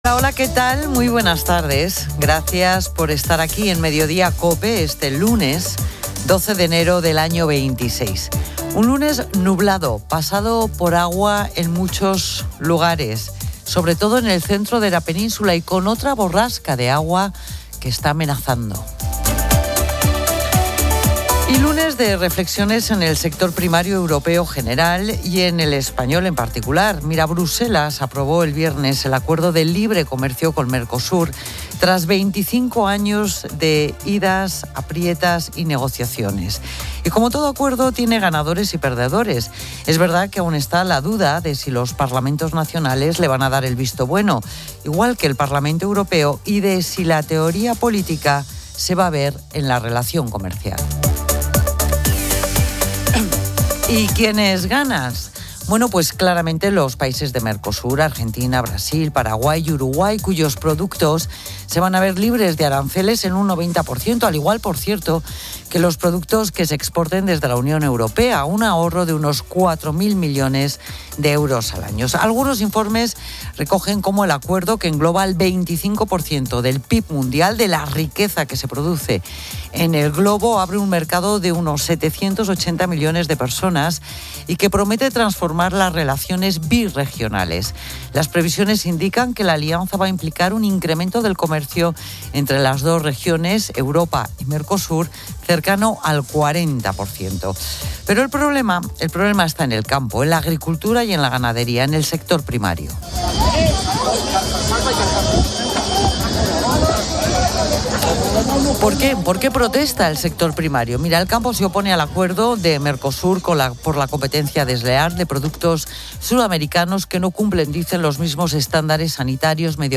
Un equipo de reporteros informa desde la zona.